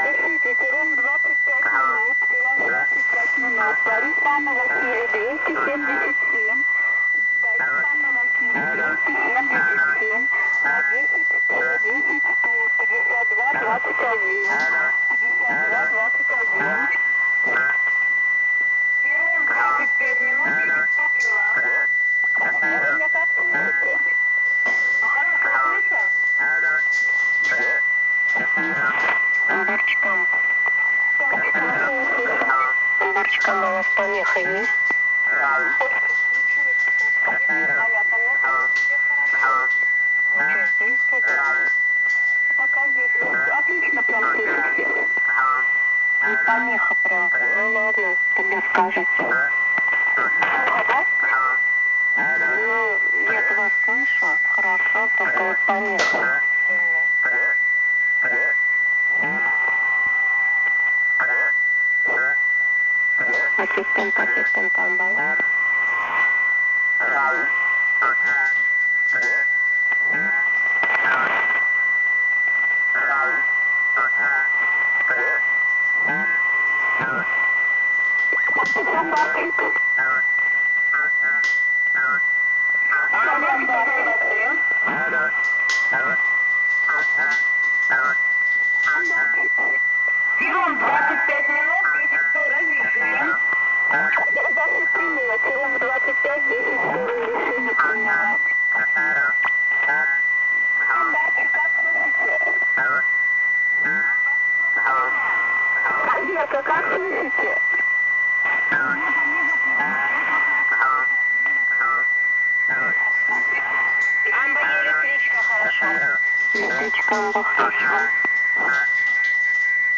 19:25 6943USB -
Video with partial recording (garbled) -
Live dialogue in SSB, probably transmitter operators.